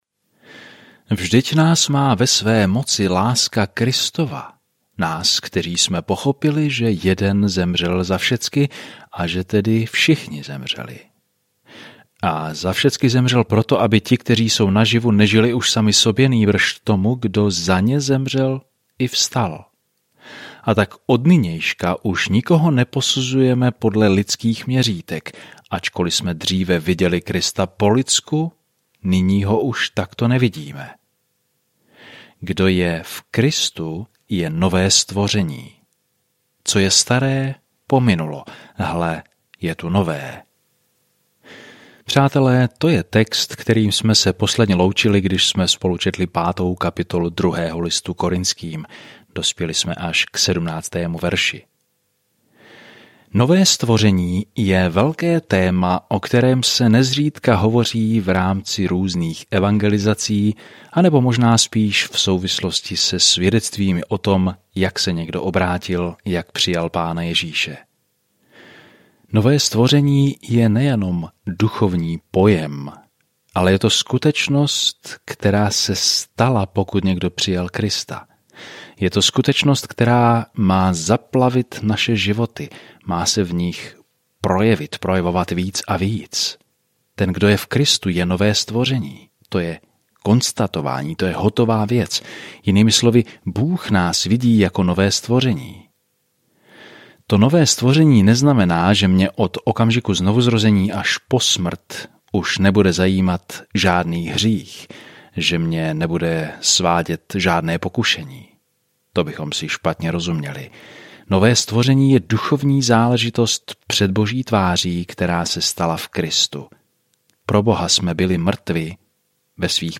Denně procházejte 2 Korinťany a poslouchejte audiostudii a čtěte vybrané verše z Božího slova.